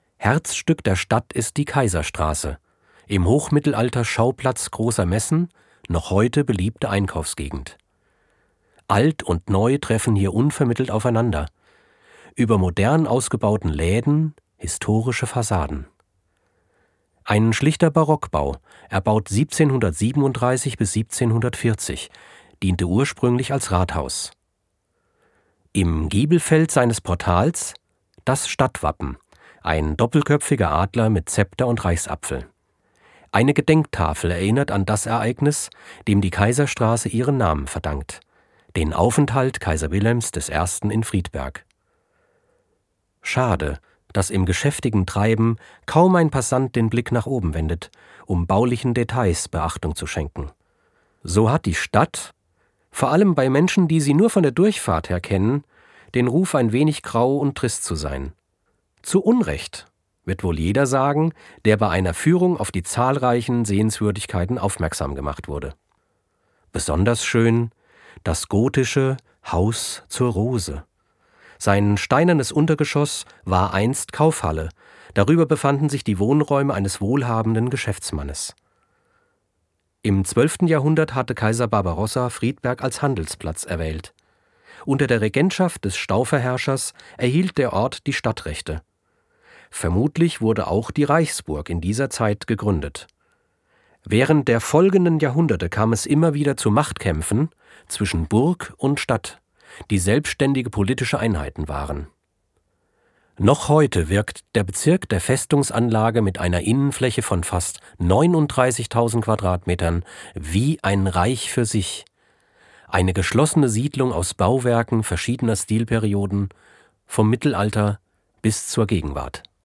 Deutscher Sprecher, tief, Kinderbuch, Sachtext, Voice-Over, Lyrik, Roman, Vorleser Arne Dahl, MP3-Home-Production (ZOOM), 6 deutsche Dialekte
Sprechprobe: eLearning (Muttersprache):